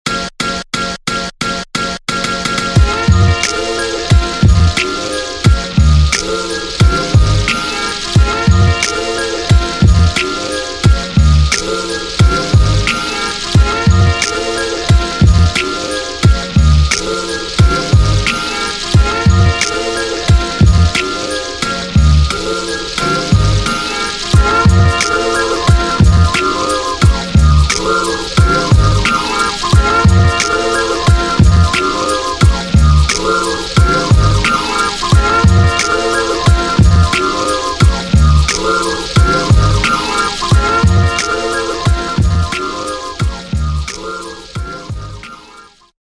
beat tape